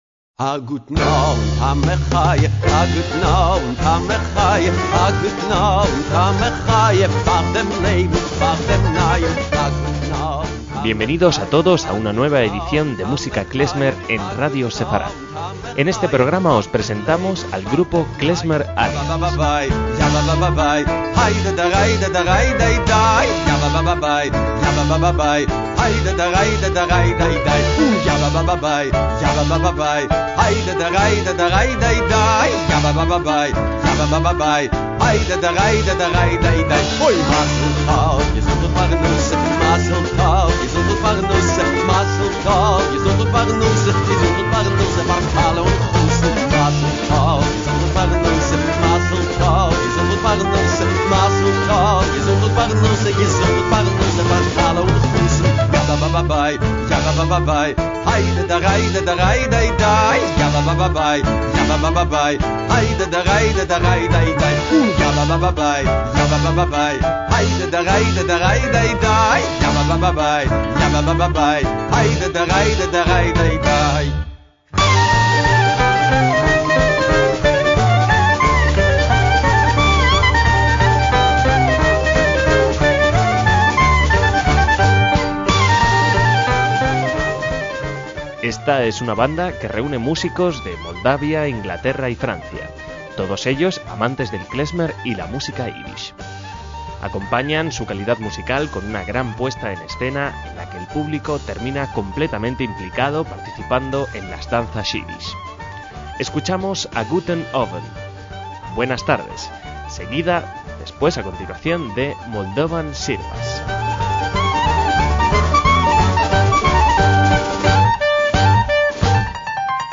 MÚSICA KLEZMER
piano
clarinetes y flauta dulce
contrabajo
guitarra y mandolina
percusiones